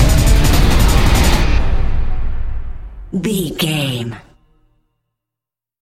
Epic / Action
Phrygian
strings
brass
drum machine
percussion
synth effects
driving drum beat